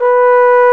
Index of /90_sSampleCDs/Roland LCDP12 Solo Brass/BRS_Trombone/BRS_Tenor Bone 2